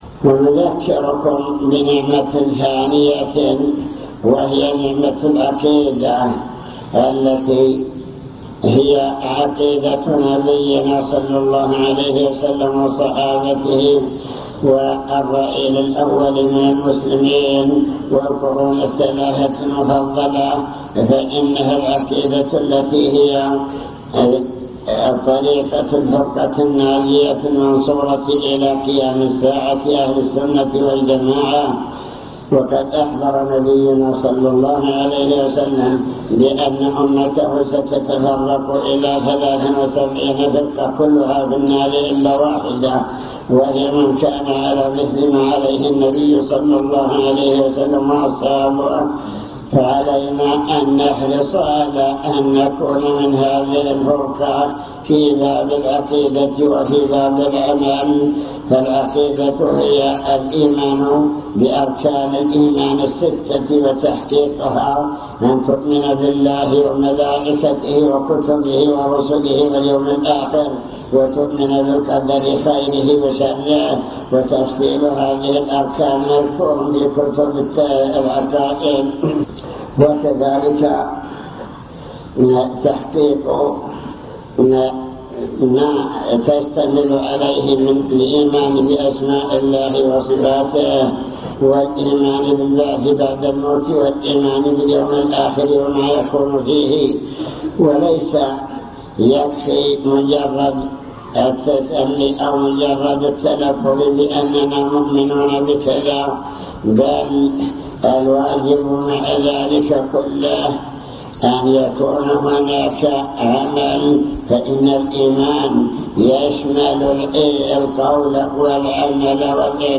المكتبة الصوتية  تسجيلات - محاضرات ودروس  محاضرة بعنوان شكر النعم (3) نماذج من نعم الله تعالى التي خص بها أهل الجزيرة